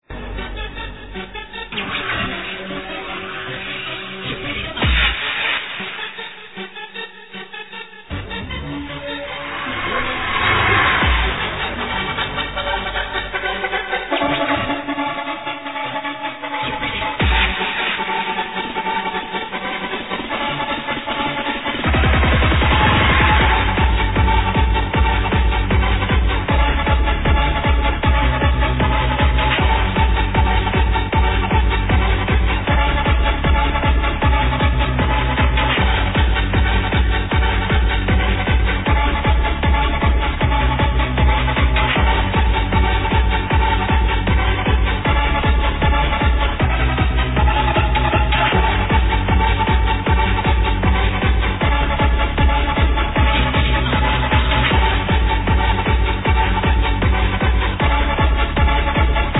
trance/hardhouse/NRG?